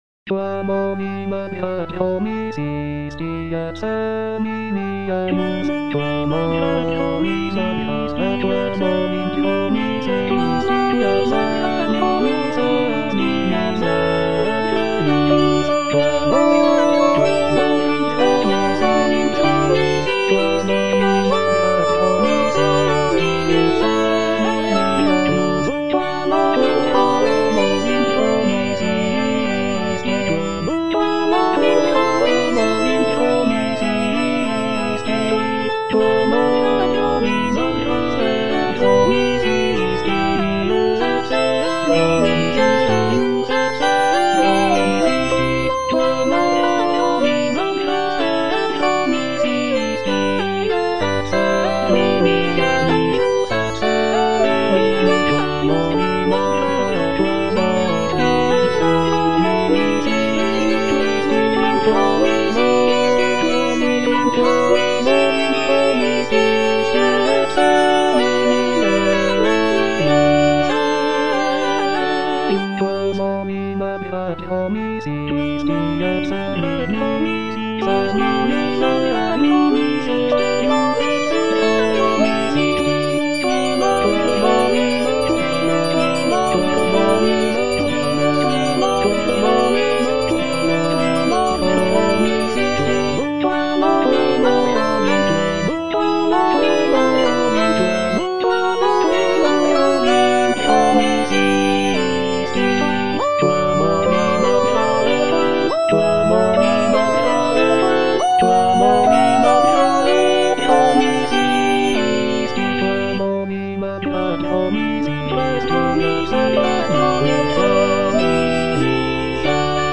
F. VON SUPPÈ - MISSA PRO DEFUNCTIS/REQUIEM Quam olim Abrahae (All voices) Ads stop: auto-stop